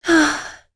Lorraine-Vox_Sigh1.wav